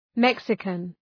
Προφορά
{‘meksıkən}